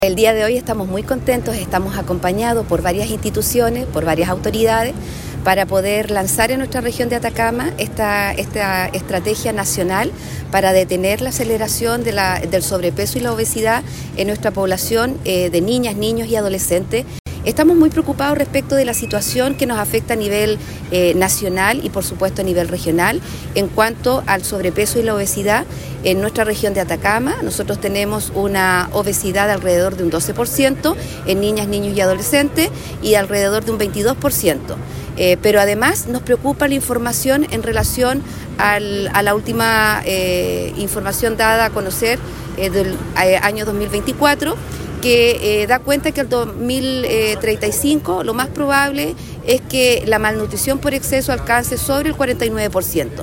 La Seremi de Salud, Jéssica Rojas, destacó la importancia de actuar de manera temprana: